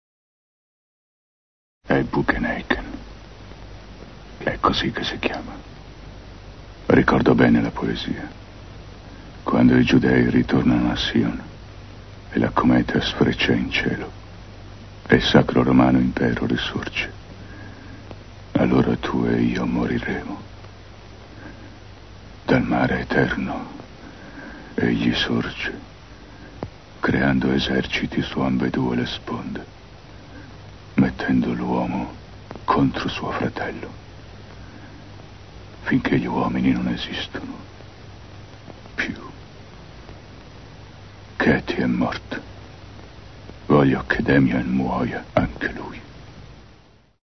voce di Massimo Foschi nel film "Il presagio", in cui doppia Gregory Peck.